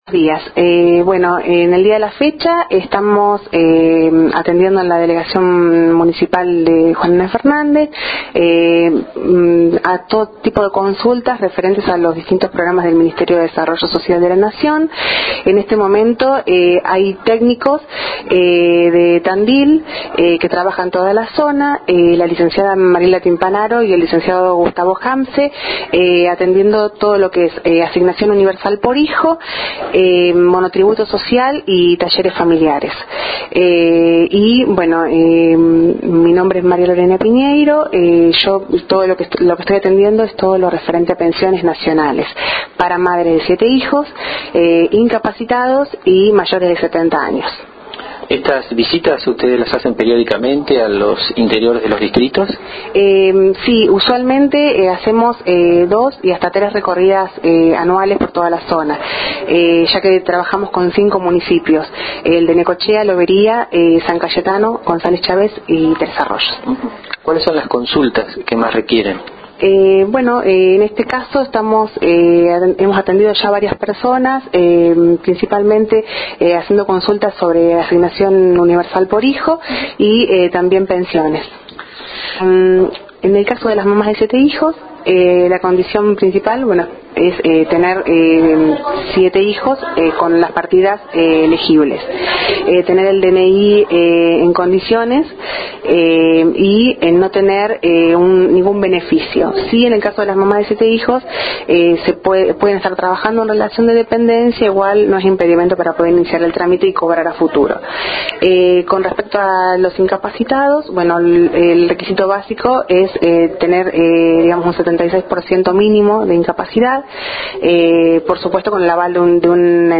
En diálogo exclusivo con